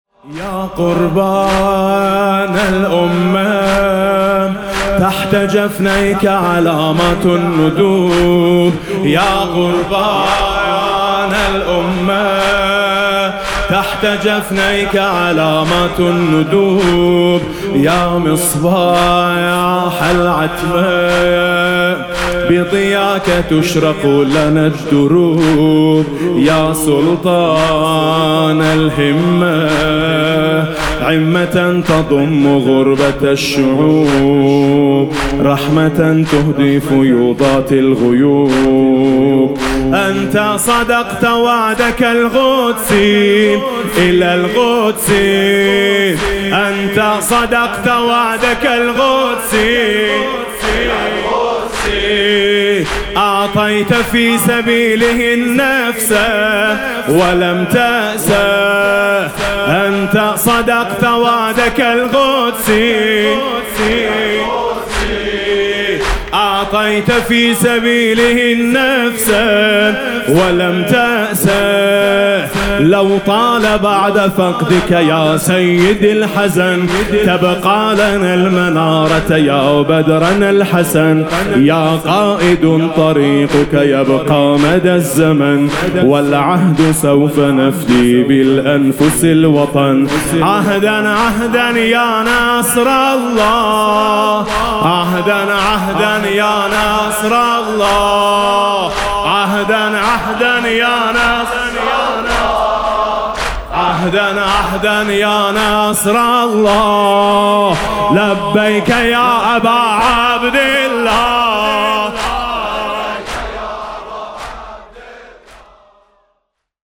اللطمیات العربیة